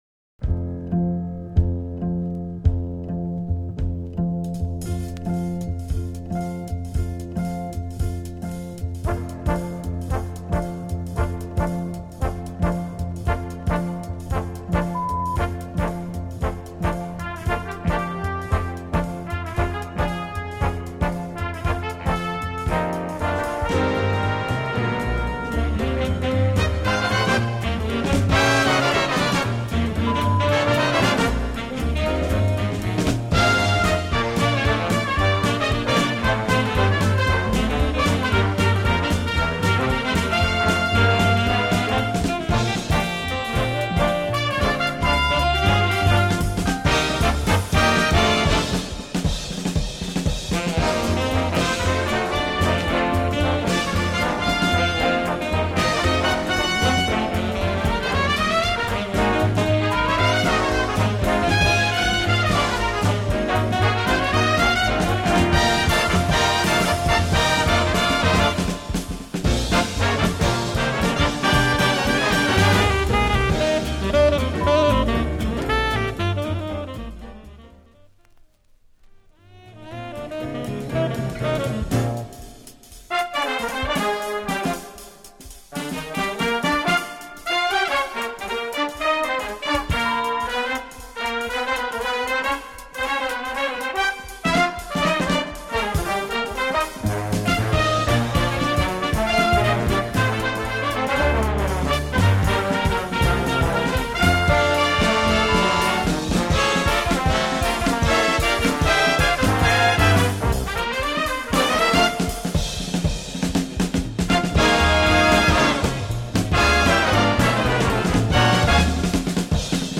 Jazz Band
Swing